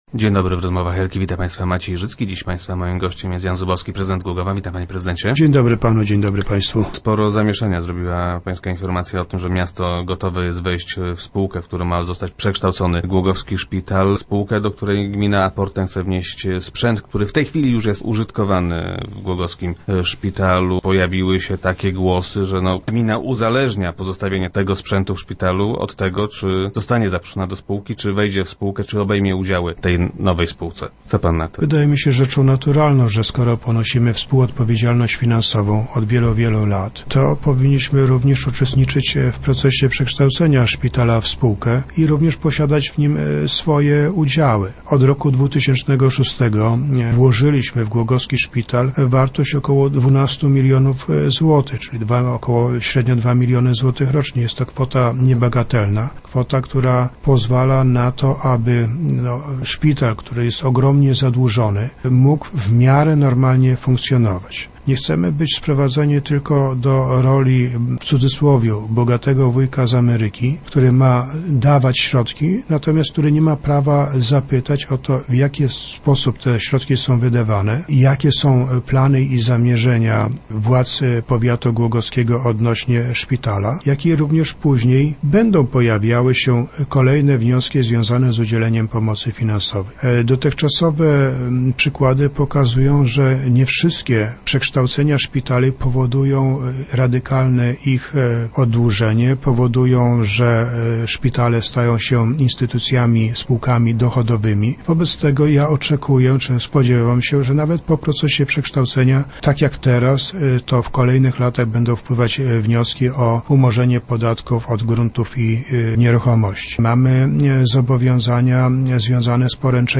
0511_zubowski_nowy.jpg- Skoro ponosimy nakłady na szpital, to powinniśmy uczestniczyć w procesie jego przekształcania - twierdzi prezydent Jan Zubowski. W Rozmowach Elki zaprzeczył, jakoby decyzję o pozostawieniu w lecznicy sprzętu użyczonego przez gminę, uzależniał od przejęcia części udziałów w spółce.
Na radiowej antenie prezydent Zubowski zapowiedział, że nawet jeśli gmina nie zostanie udziałowcem w medycznej spółce, sprzęt użyczony lecznicy nadal będzie służył pacjentom.